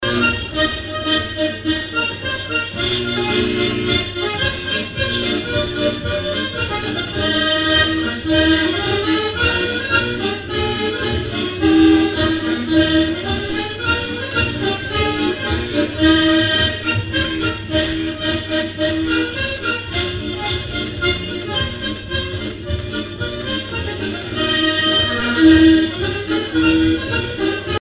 danse : polka des bébés ou badoise
airs pour animer un bal
Pièce musicale inédite